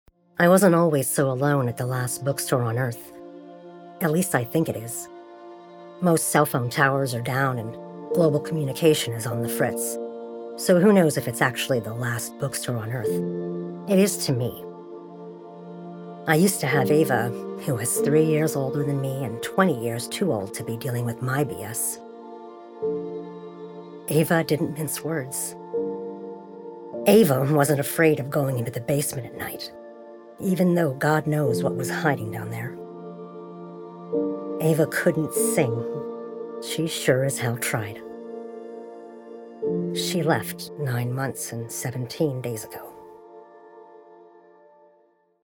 Audiobook Narration
I have a naturally rich, deep voice that exudes confidence while maintaining authenticity and relatability.